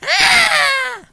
邪恶书呆子包 " 书呆子尖叫